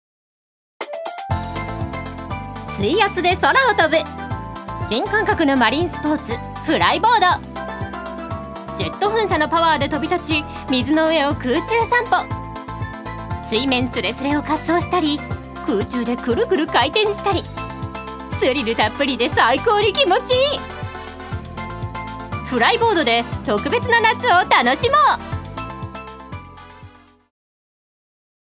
女性タレント
ナレーション５